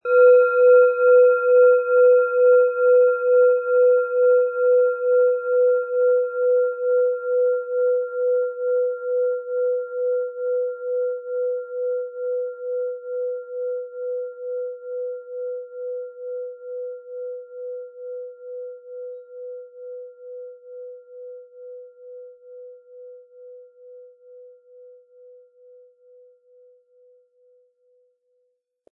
Planetenschale® Lebensmut haben & Lebenslustig sein mit Delfin-Ton, Ø 11,7 cm, 320-400 Gramm inkl. Klöppel
Planetenton 1
Delphin
Im Sound-Player - Jetzt reinhören können Sie den Original-Ton genau dieser Schale anhören.
HerstellungIn Handarbeit getrieben
MaterialBronze